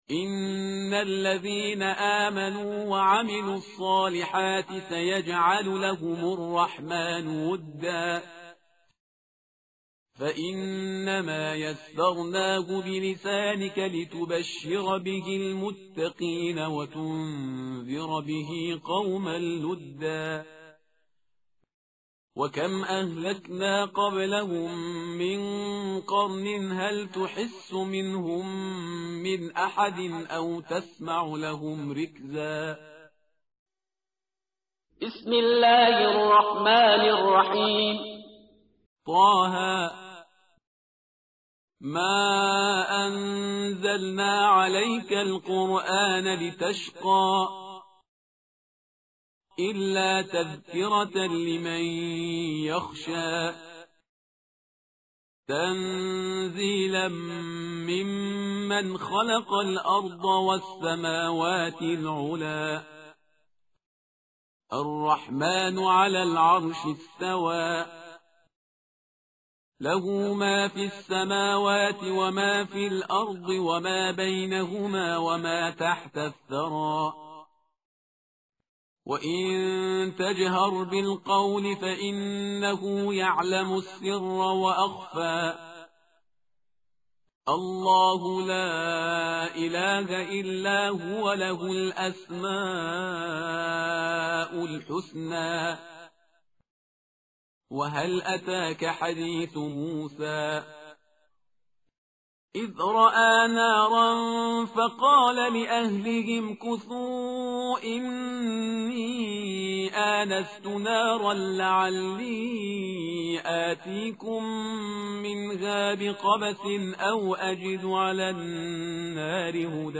tartil_parhizgar_page_312.mp3